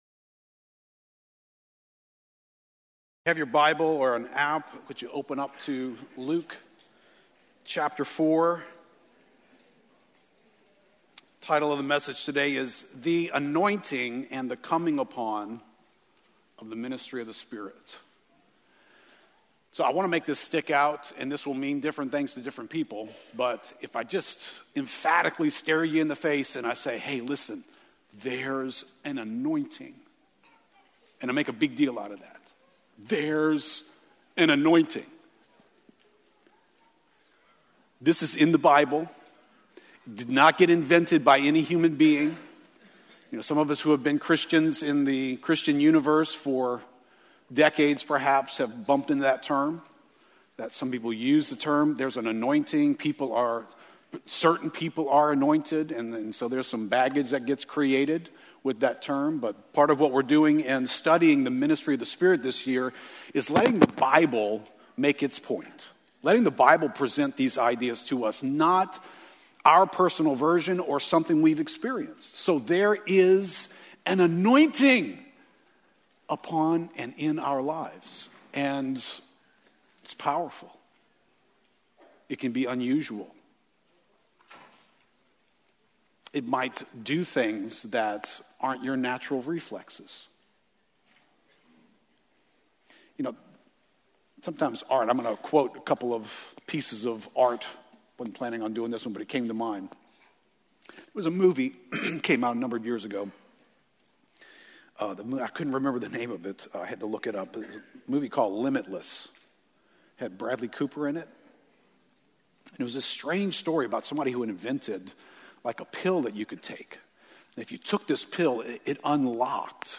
Welcome to the weekly sermon podcast of Lakeview Christian Center in New Orleans, LA.
Lakeview Christian Center - Sunday Sermons